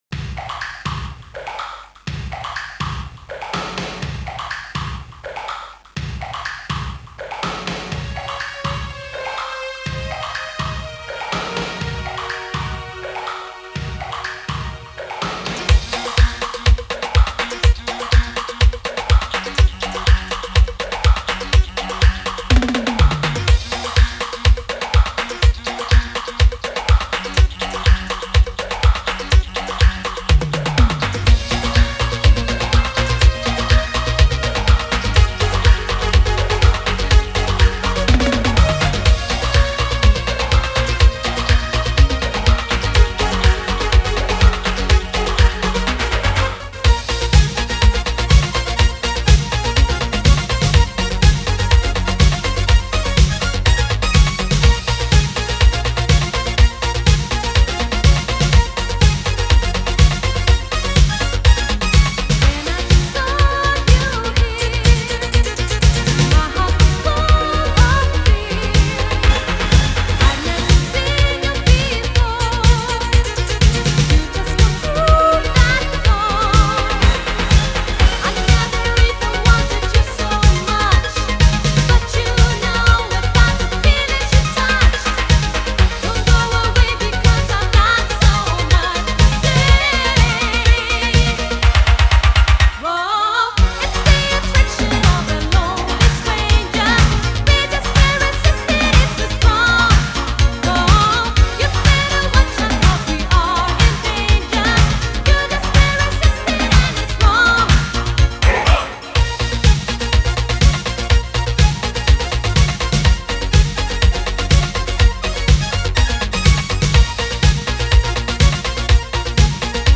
That certainly sounds period-appropriate.